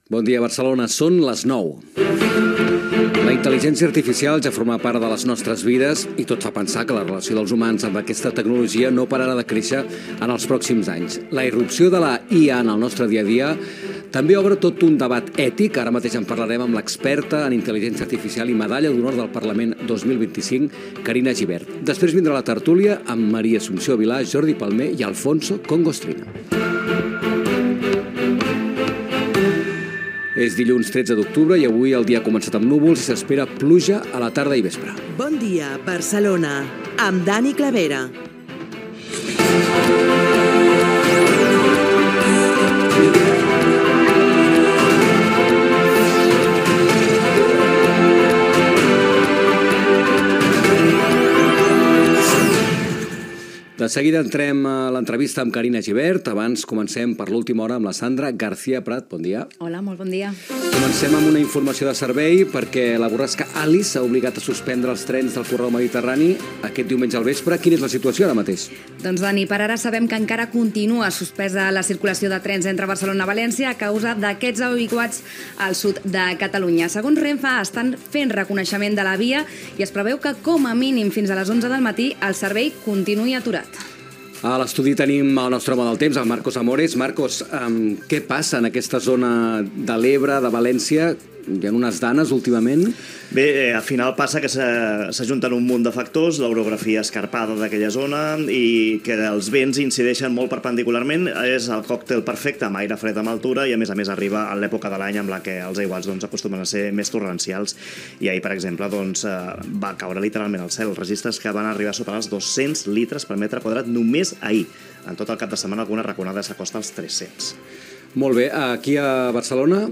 Hora, sumari de continguts, data, estat del temps, indicatiu, informació dels aiguats, obres a la Gran Via, sorteig d'habitatge protegit, manifestacions del 12 d'octubre, indicatiu, presentació i entrevista
Gènere radiofònic Info-entreteniment